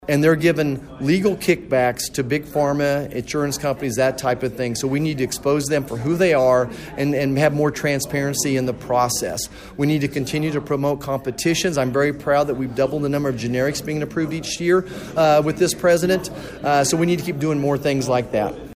MANHATTAN — Congressman Roger Marshall was back in Manhattan Saturday, hosting a town hall discussion with about two dozen constituents at the Sunset Zoo’s Nature Exploration Place.